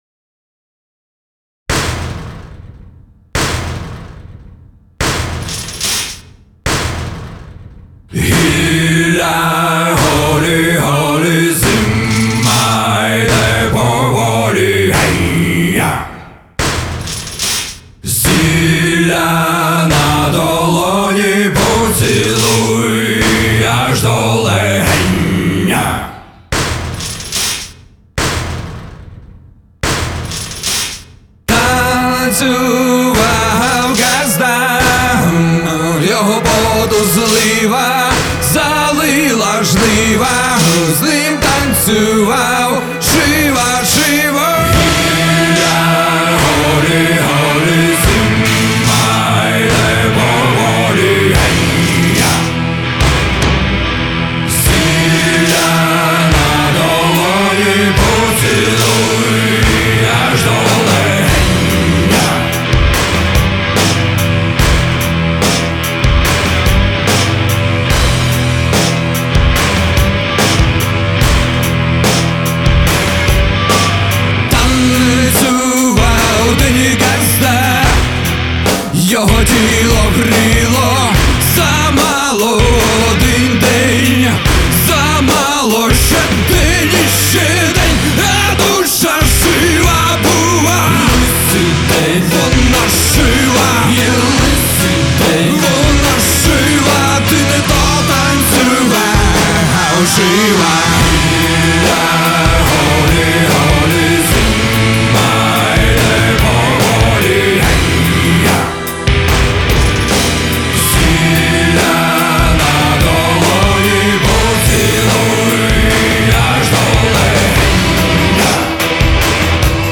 Обезбашенная песенка